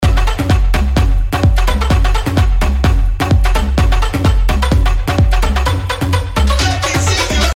не могу понять что в слоях с басом идет. слышу верхушку от Тайко или Том, но что сам бас играет не слышу. важна сама основа помогите кто может )